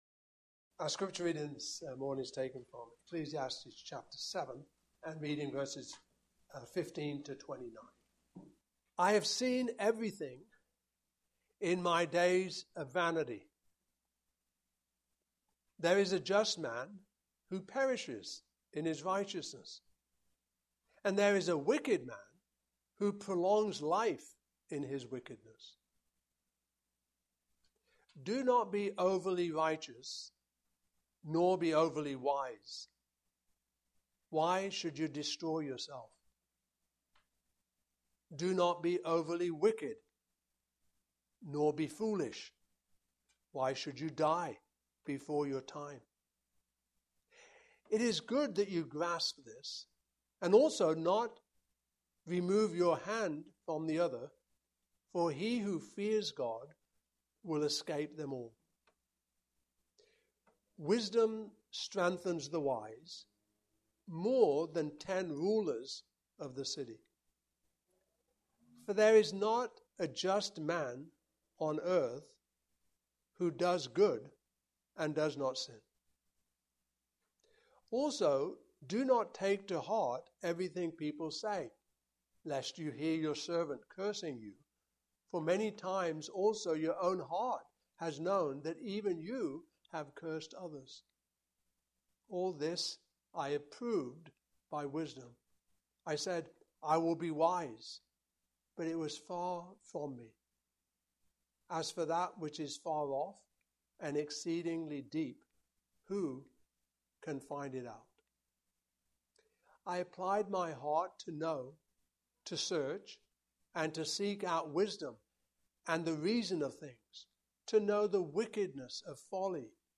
Passage: Ecclesiastes 7:13-29 Service Type: Morning Service